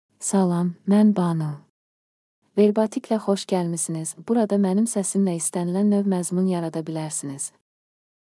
Banu — Female Azerbaijani (Latin, Azerbaijan) AI Voice | TTS, Voice Cloning & Video | Verbatik AI
BanuFemale Azerbaijani AI voice
Banu is a female AI voice for Azerbaijani (Latin, Azerbaijan).
Voice sample
Listen to Banu's female Azerbaijani voice.
Female